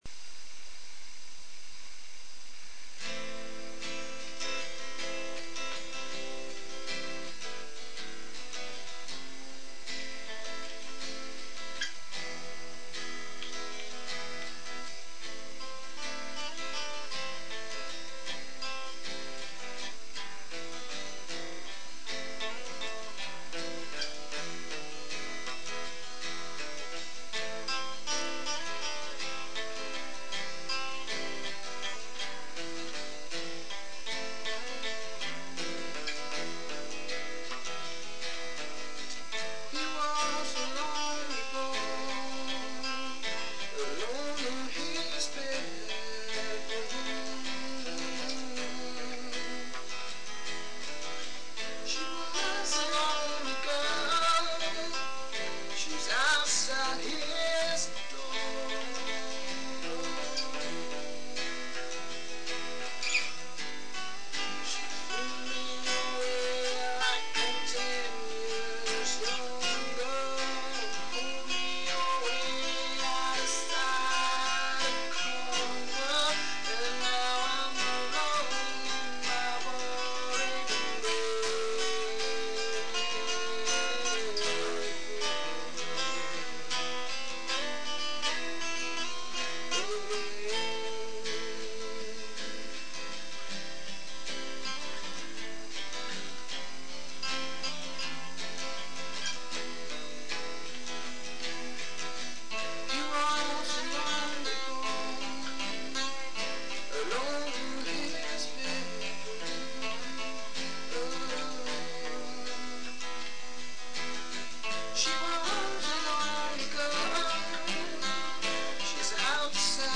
Here's some song that we recorded ourselves.